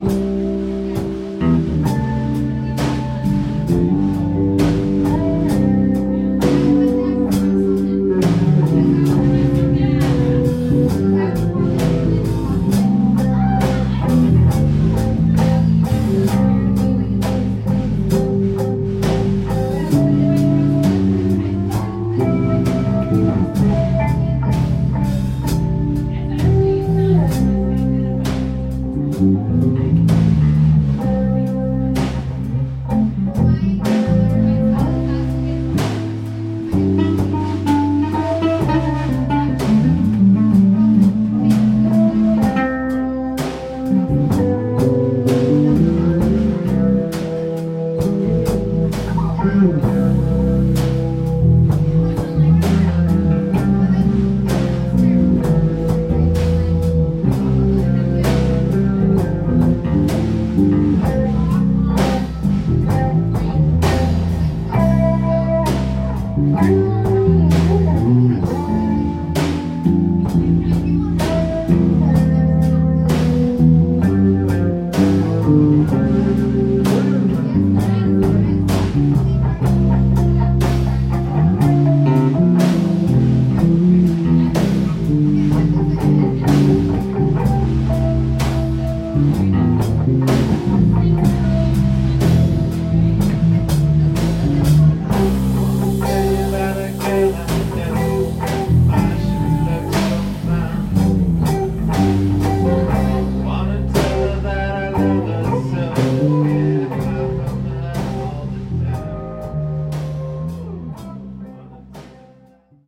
09-This is the 'good one' nice guitar [ 1:53 ] Play Now | Play in Popup | Download
09-This-is-the-good-one-nice-guitar.mp3